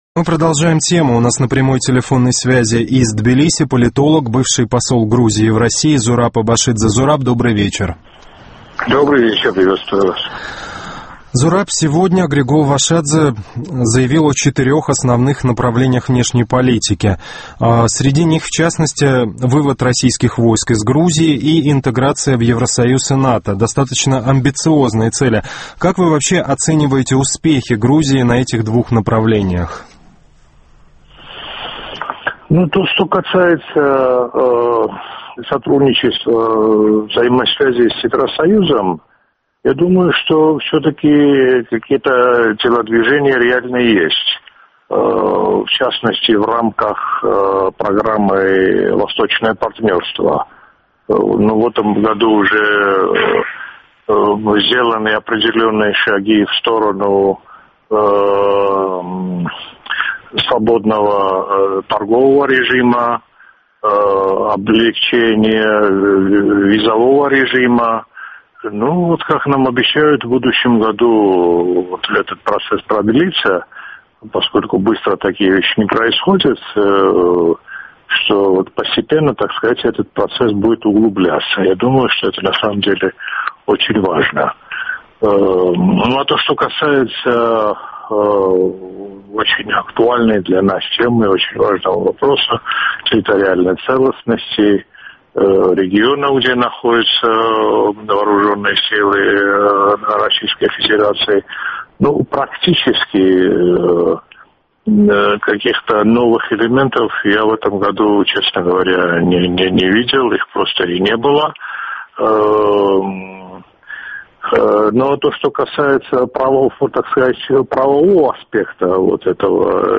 ПРАГА--Продолжаем тему.